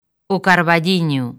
Transcripción fonética
okaɾβaˈʎiɲo̝